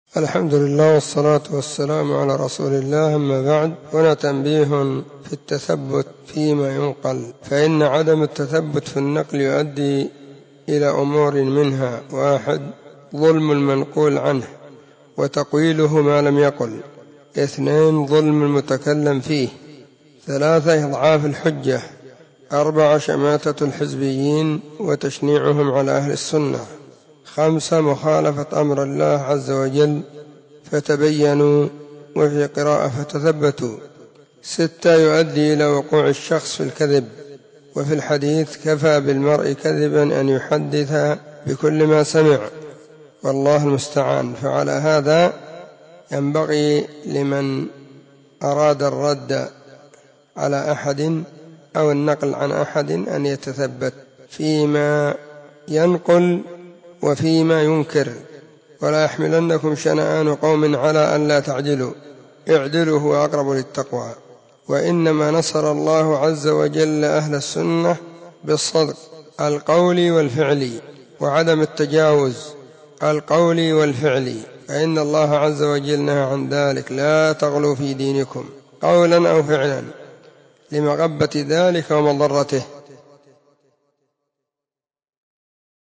نصيحة قيمة بعنوان *نصيحة عامة في التثبت*